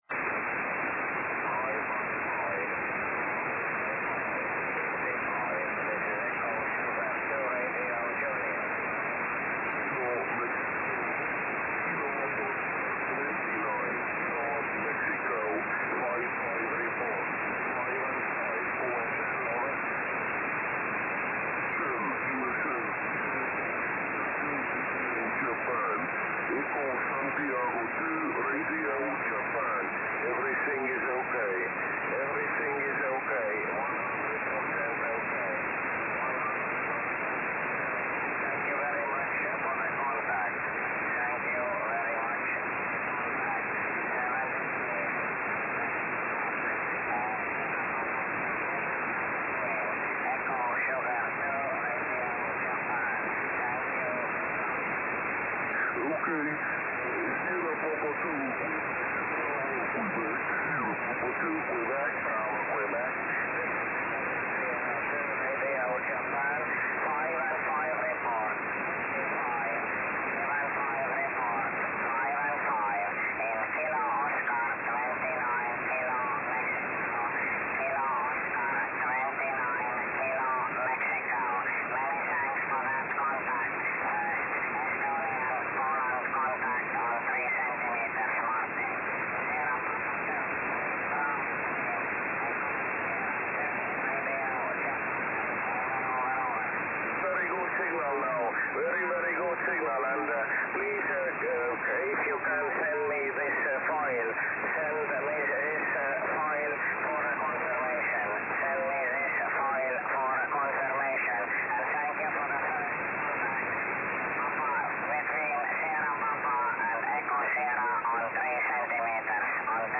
Pierwsza łączność SP- ES w paśmie 10 GHz,  rekordowy ODX